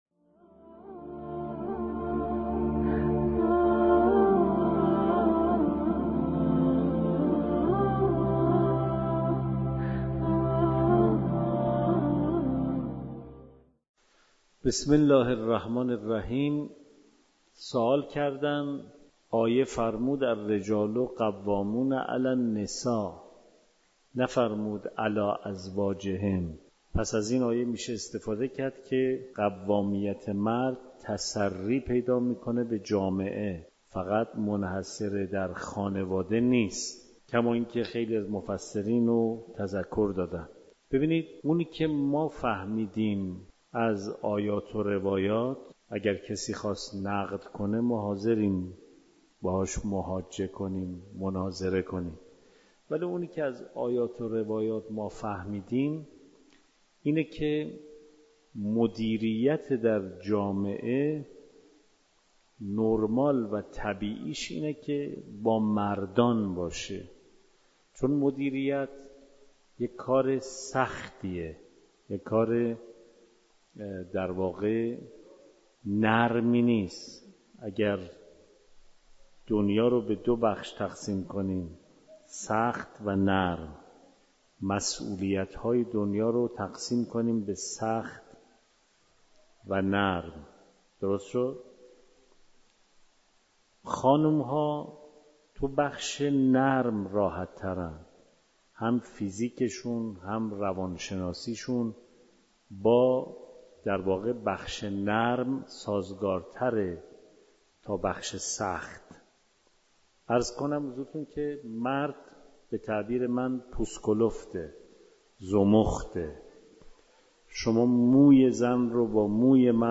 مکان: دارالتفسیر حرم مطهر رضوی